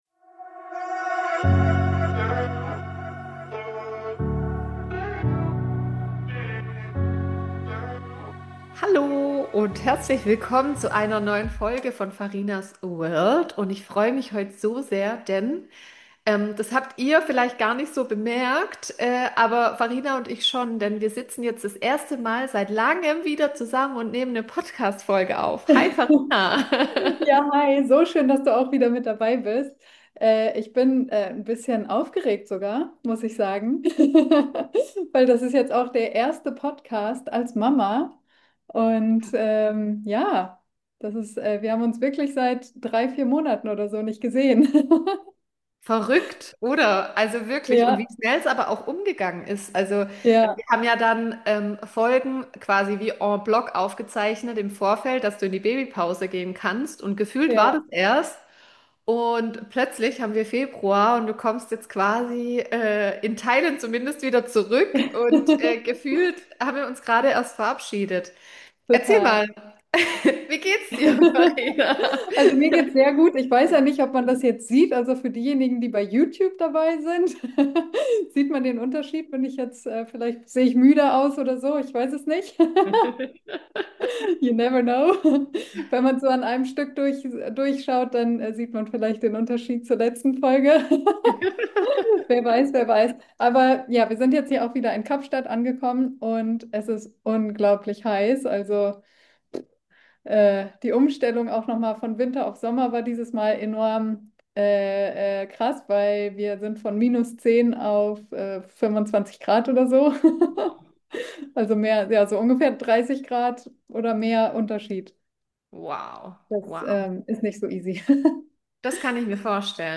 Es war authentisch, ehrlich und ein echtes Wieder-Ankommen. Ich spreche über meine Schwangerschaft, die Geburt, die ersten Wochen als Mama und darüber, wie sich mein Leben, mein Alltag und auch mein Blick auf mein Business verändert haben. Eine persönliche Folge voller echter und authentischer Einblicke (inkl. kleinem Gast).